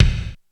Kick 01.wav